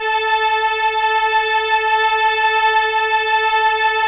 Index of /90_sSampleCDs/Roland LCDP09 Keys of the 60s and 70s 1/STR_ARP Strings/STR_ARP Solina